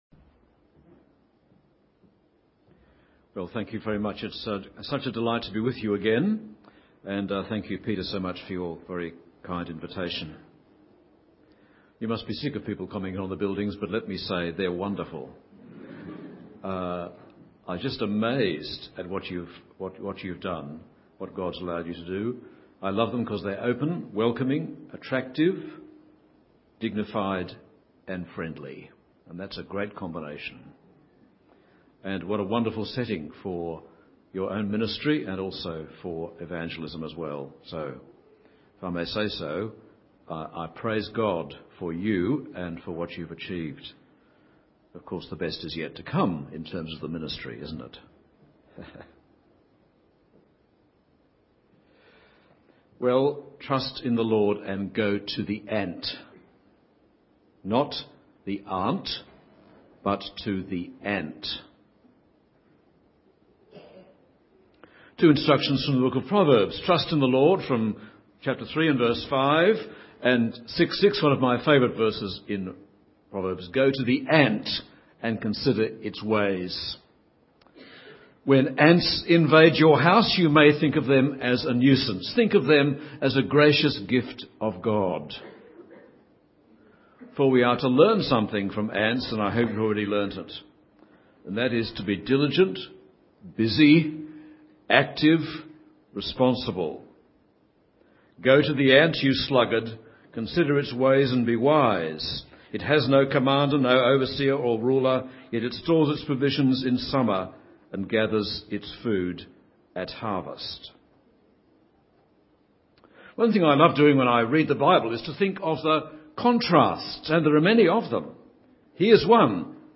Sermons | St Alfred's Anglican Church
Guest Speaker